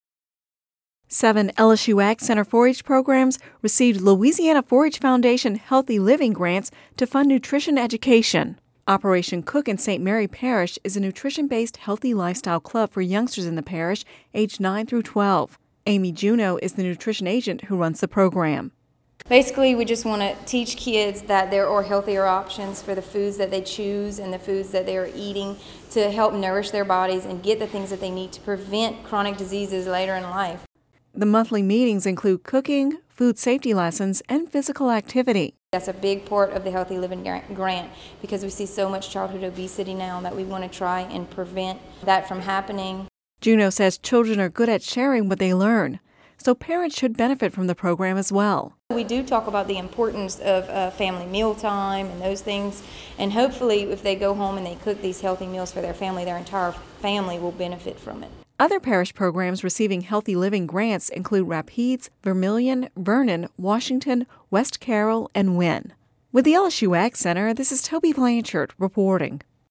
(Radio News 10/04/10) Seven LSU AgCenter 4-H programs received Louisiana 4-H Foundation healthy living grants to fund nutrition education. Operation Cook in St. Mary Parish is a nutrition-based healthy lifestyle club for youngsters ages 9 through 12.